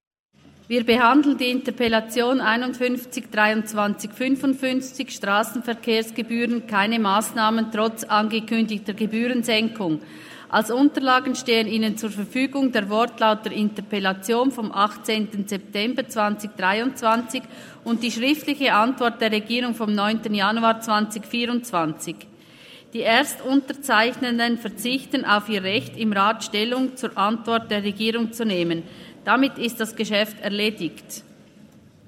21.2.2024Wortmeldung
Session des Kantonsrates vom 19. bis 21. Februar 2024, Frühjahrssession